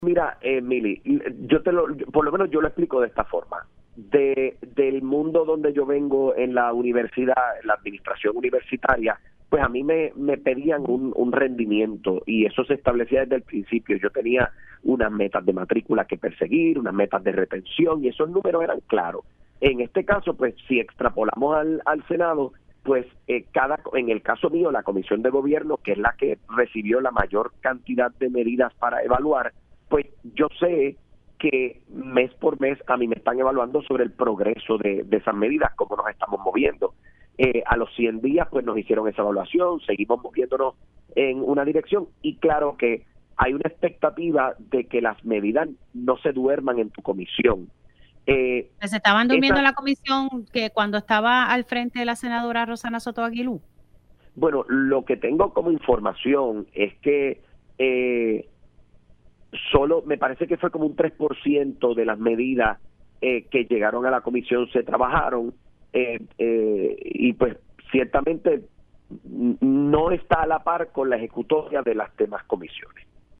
El senador Ángel Toledo reveló en Pega’os en la Mañana que bajo la presidencia de Roxanna Soto, la Comisión de lo Jurídico sólo atendió el 3 por ciento de las medidas que llegaron durante esta sesión legislativa.